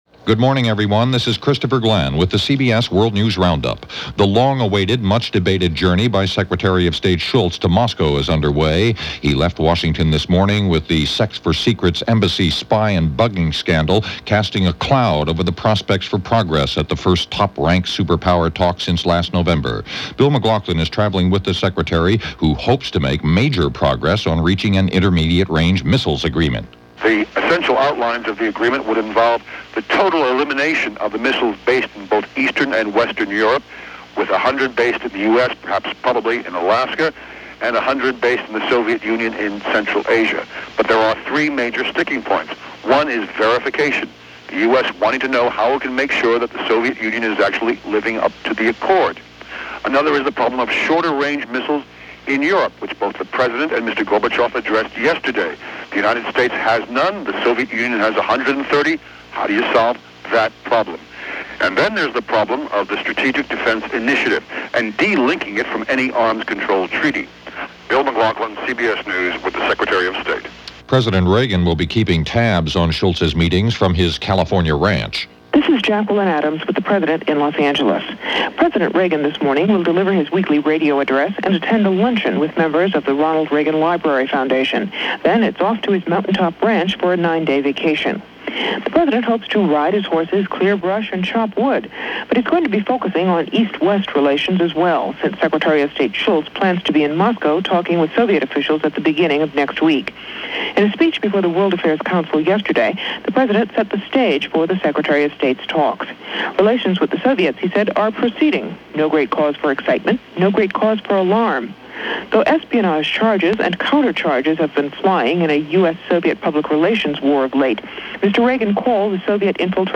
All that, sex scandals and a bunch more for this April 11, 1987 as reported by The CBS World News Roundup.